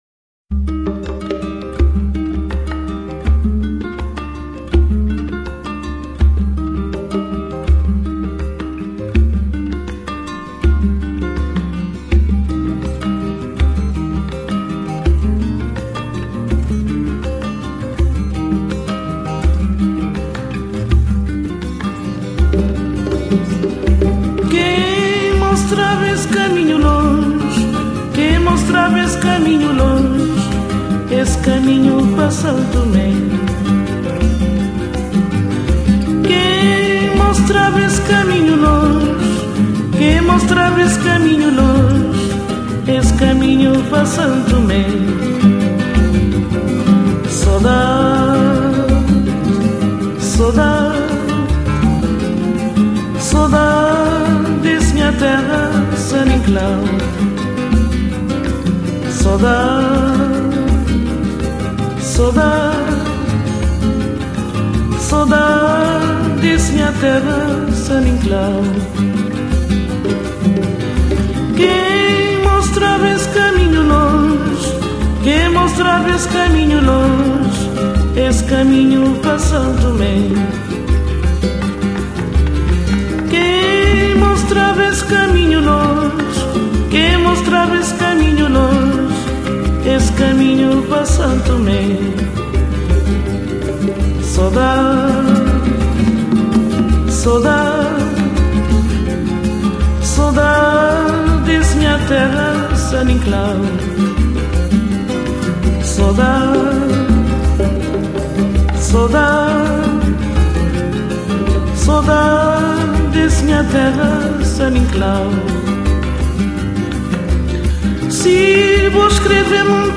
Morna 音樂和布魯斯類似，都是表達生命的苦難。